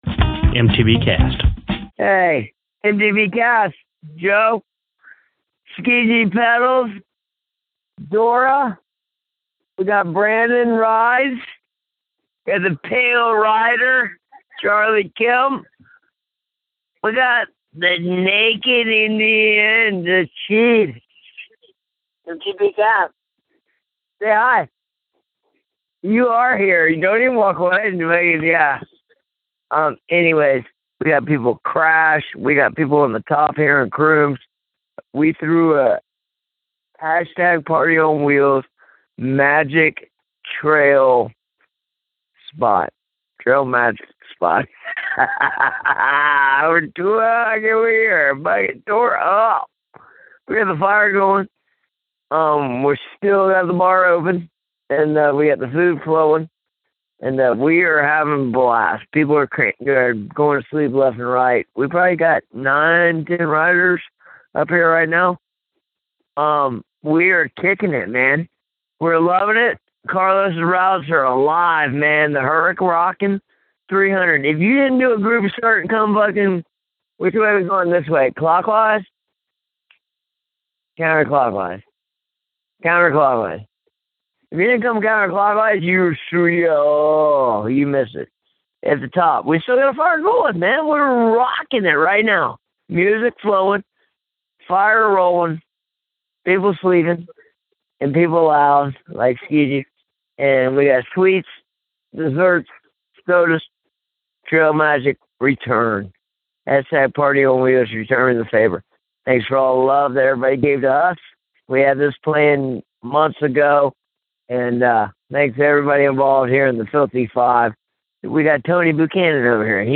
The Transformer Tandem team called in from the finish!